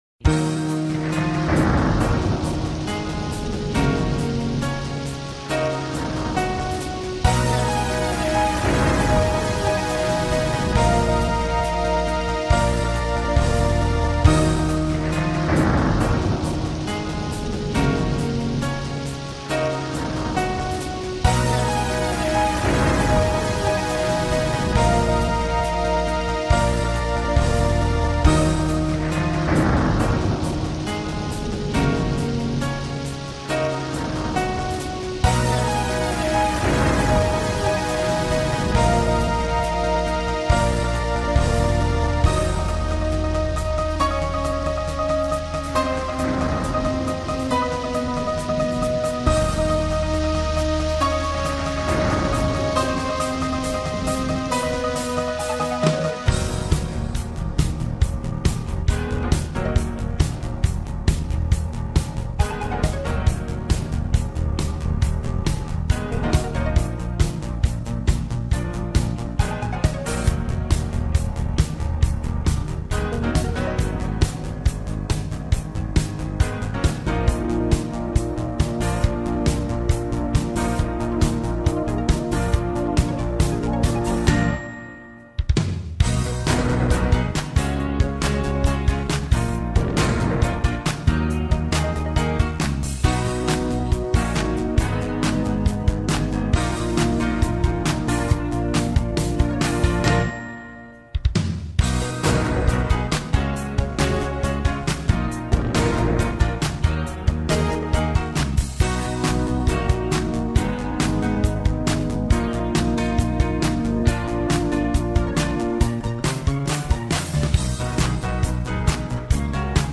instrumentaal